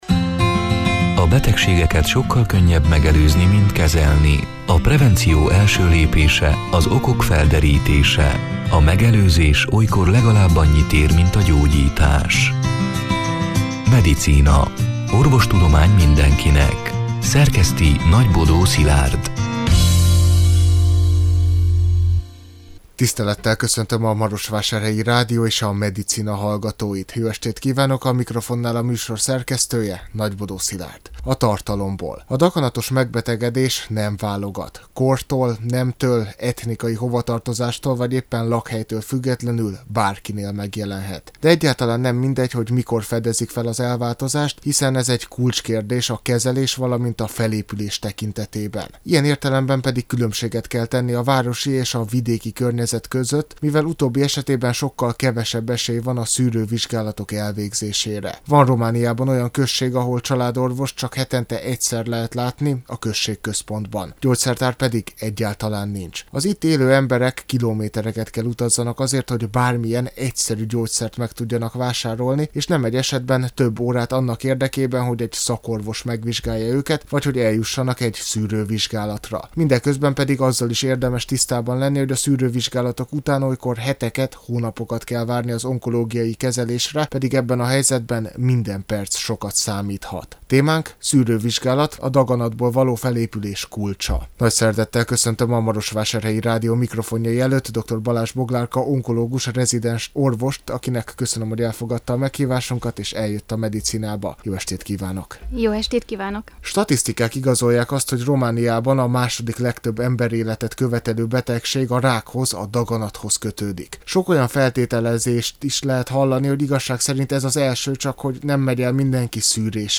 A Marosvásárhelyi Rádió Medicina (elhangzott: 2024. november 27-én, szerdán este nyolc órától) c. műsorának hanganyaga: